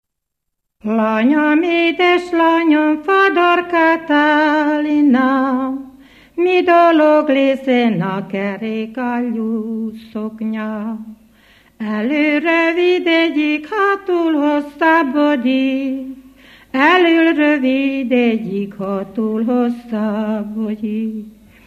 Felföld - Nyitra vm. - Zsére
ének
Műfaj: Ballada
Stílus: 7. Régies kisambitusú dallamok
Kadencia: 4 (b3) 1 1